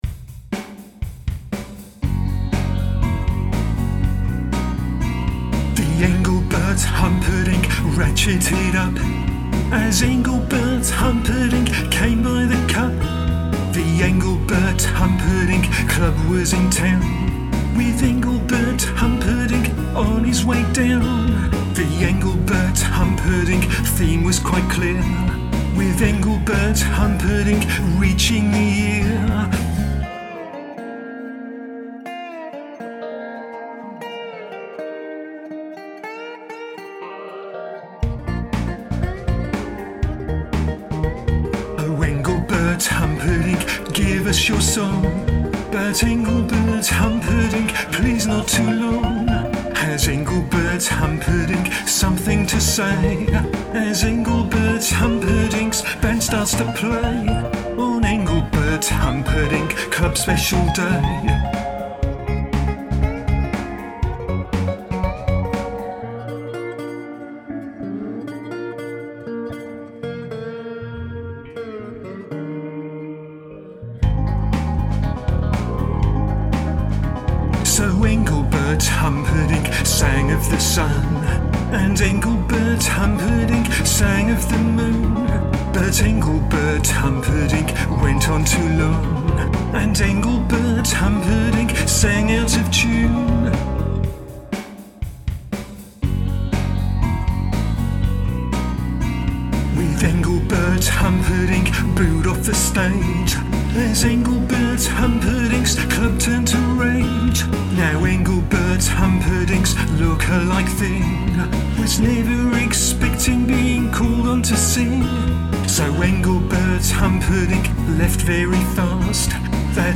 I got the lead vocal quite quickly, but spent quite a long time tinkering with the backing vocal.